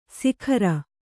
♪ sikhara